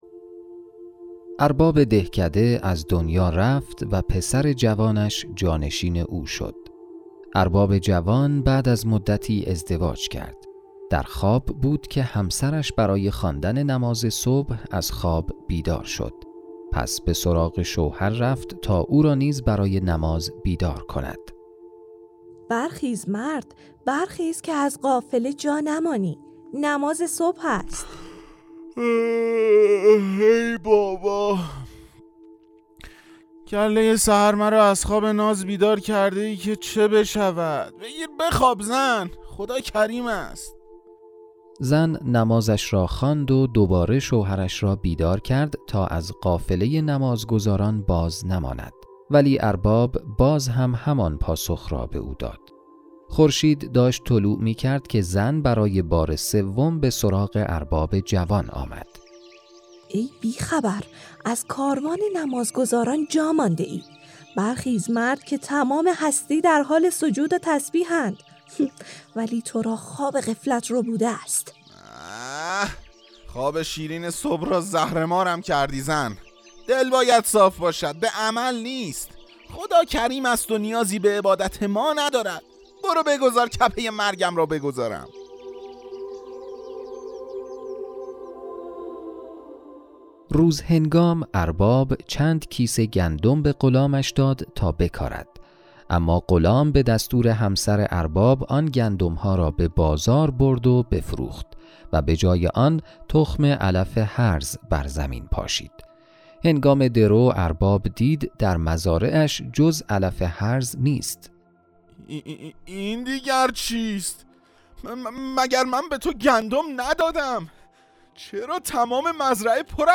دلت پاک باشه - داستان صوتی کوتاه 179 - میقات مدیا
صداپیشگان :